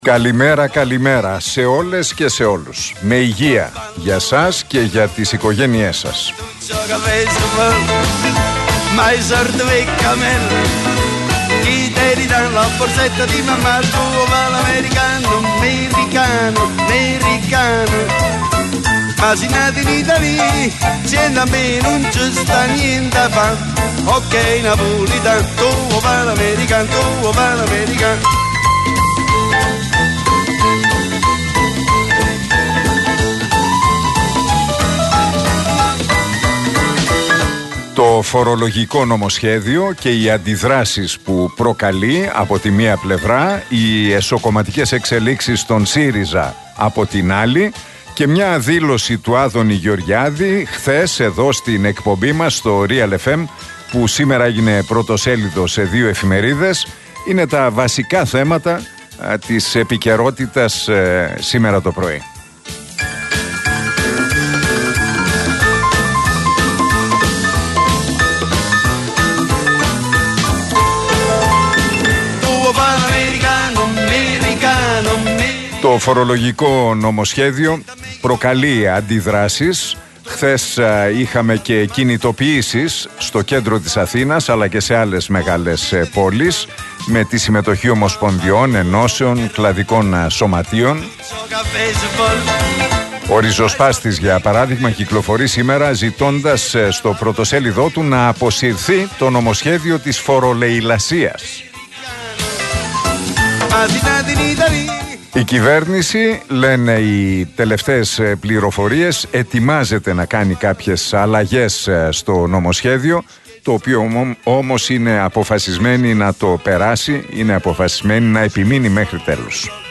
Ακούστε το σχόλιο του Νίκου Χατζηνικολάου στον RealFm 97,8, την Πέμπτη 9 Νοεμβρίου 2023.